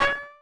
New UI SFX
ui_accept_v1.wav